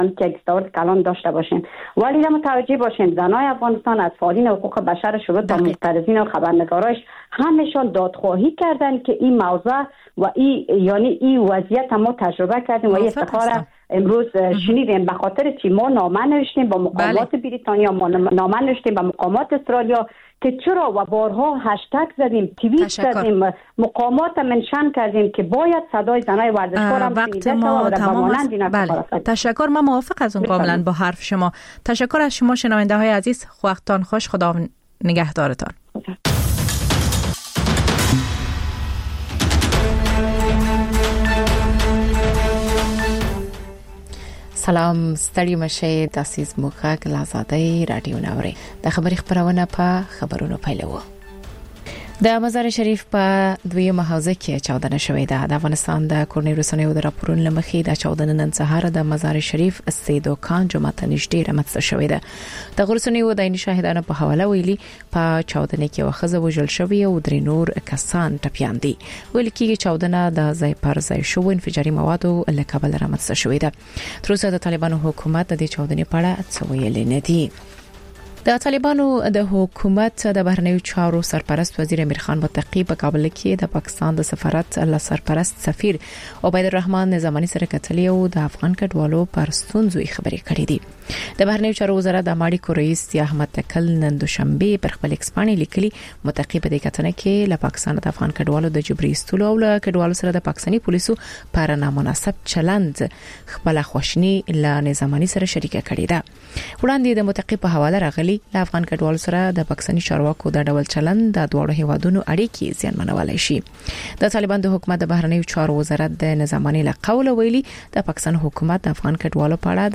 خبرونه او راپورونه